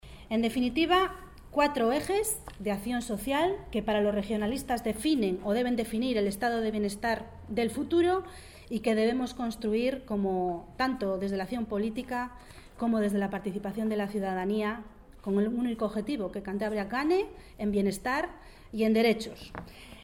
Todo con un objetivo claro que “los cántabros ganen en derechos y bienestar” ha sostenido la diputada regionalista Rosa Valdés durante la presentación de la ponencia ‘Cantabria ante los nuevos retos de las políticas del bienestar. Por una ciudadanía social plena’.